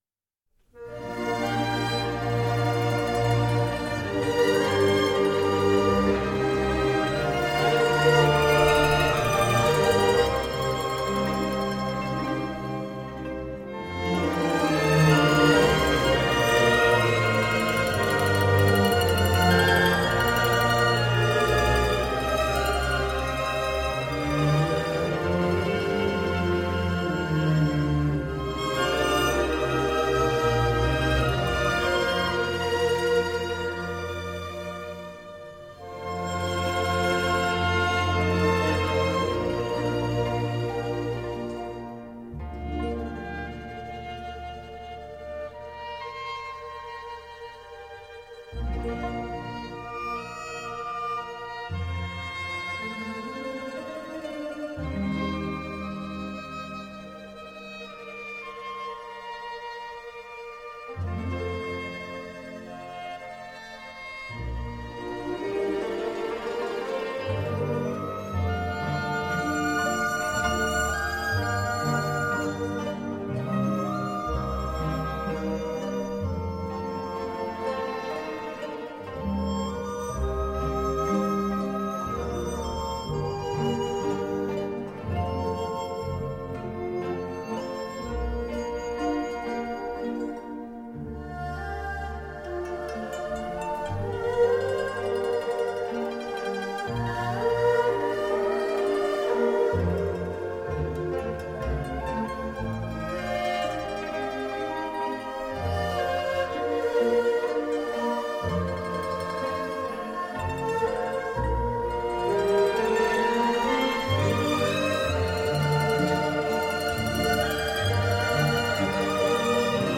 中国民族管弦乐及小品
这是一张以中国乐器演奏各国民歌乐曲呈现出不同的韵味CD。